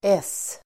Uttal: [es:]